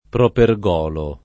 [ proper g0 lo ]